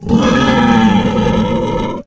boar_death_2.ogg